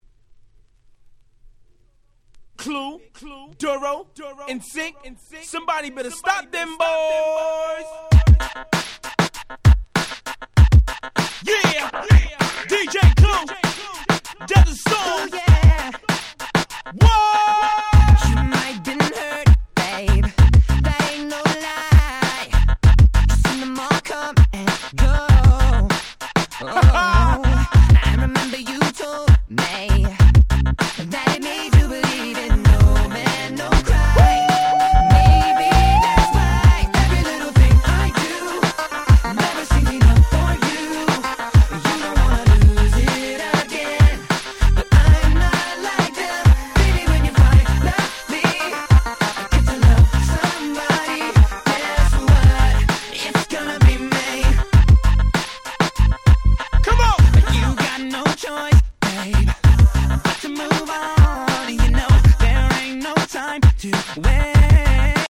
00' Super Hit Pops / R&B !!